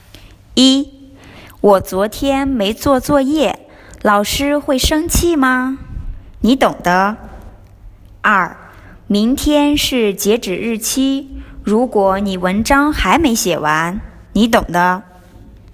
你懂的 (nǐ dǒng de) = You know it.